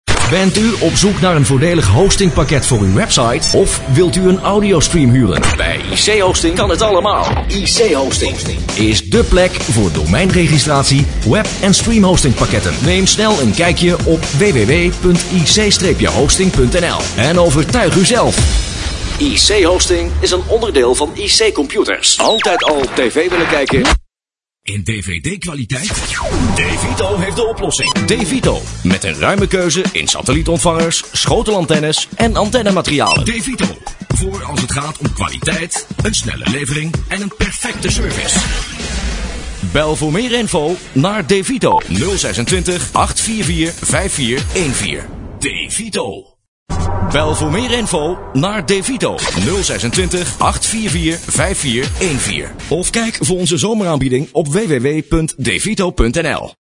Commercials
Op deze pagina tref je een aantal voorbeelden aan van commercials die de afgelopen tijd door mij zijn ingesproken voor diverse lokale-, regionale- en interrnet-radiostations.